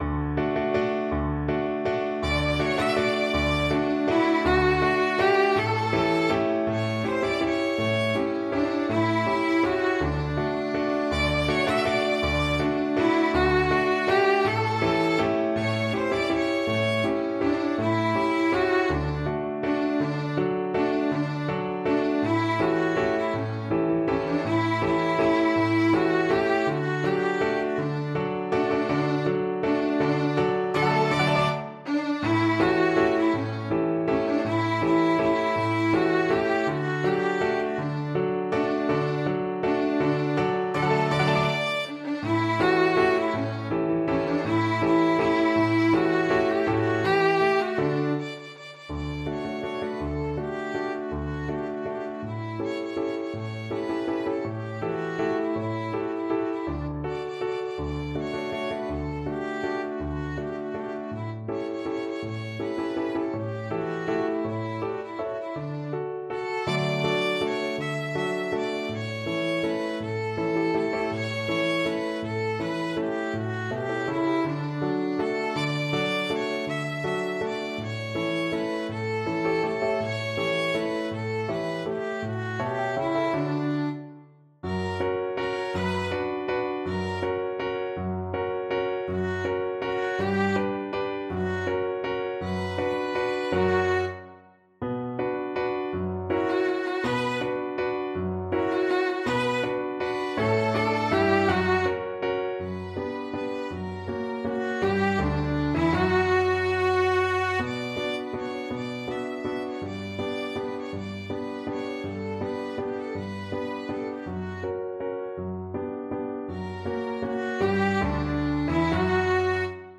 Free Sheet music for Violin Duet
Violin 1Violin 2Piano
3/4 (View more 3/4 Music)
D major (Sounding Pitch) (View more D major Music for Violin Duet )
Lively one in a bar . = c. 54
Classical (View more Classical Violin Duet Music)